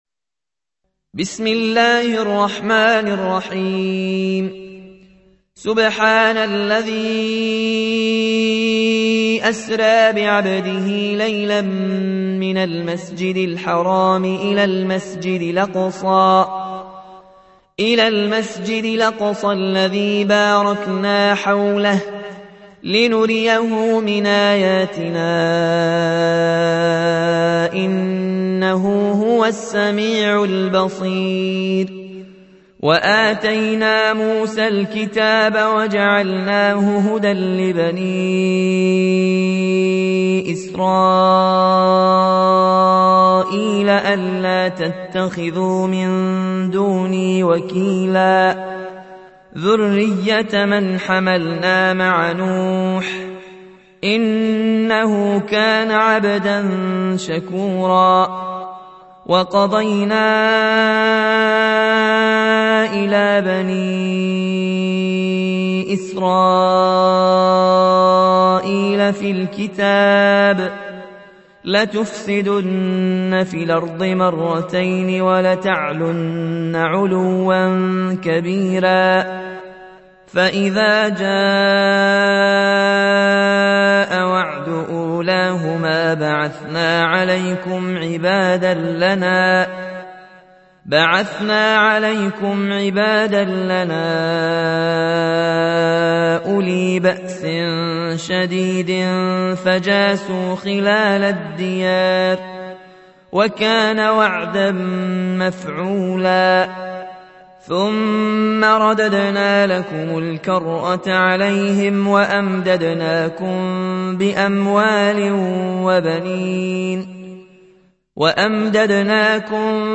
17. سورة الإسراء / القارئ